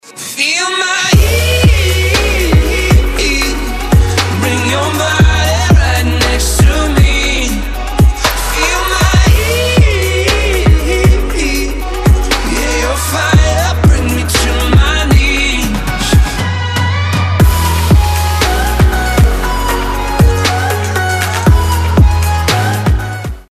• Качество: 256, Stereo
позитивные
Electronic
Trap
club
vocal